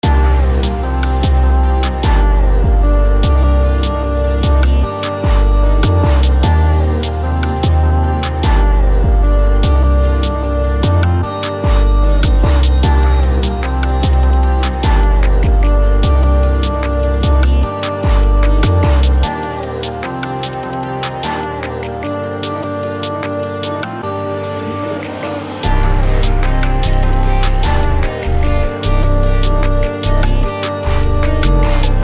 fakemink feng type beat
BPM: 150
Key: Bb Major